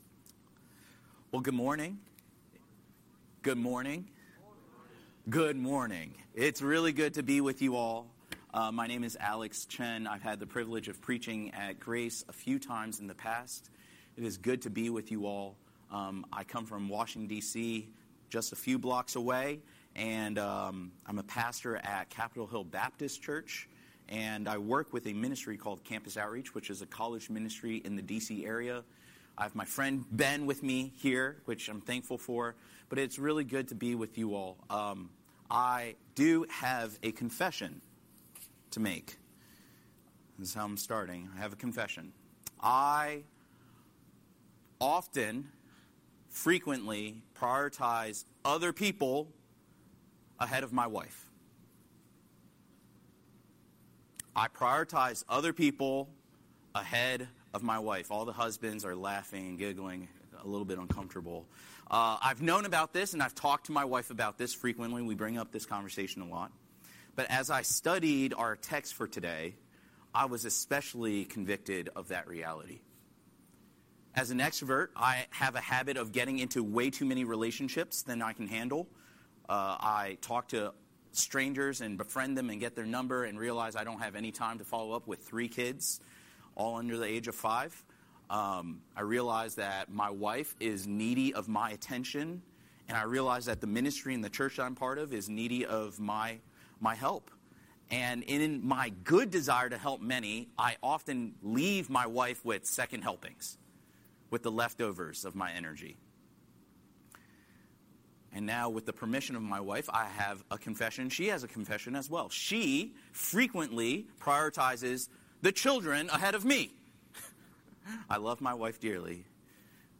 Scripture: Haggai 1:1–15 Series: Sunday Sermon